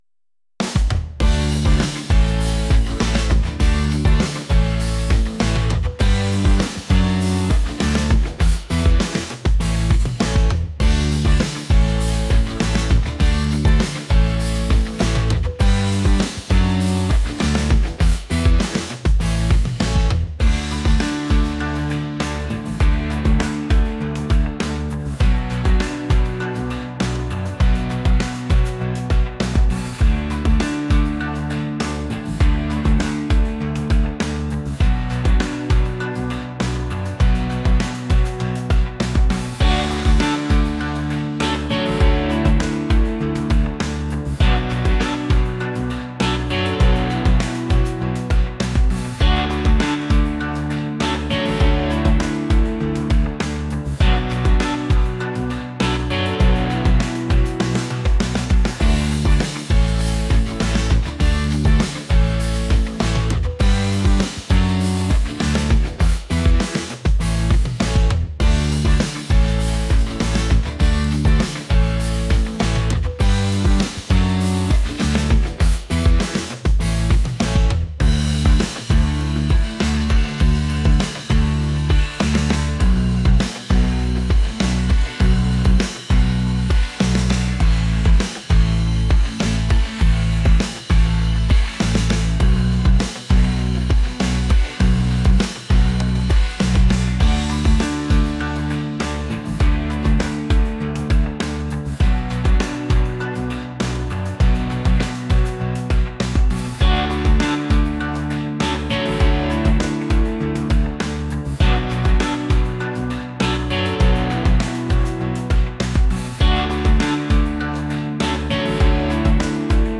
upbeat